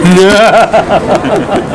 stelarc.laugh.wav